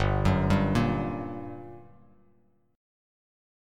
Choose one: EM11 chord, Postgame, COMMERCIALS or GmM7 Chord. GmM7 Chord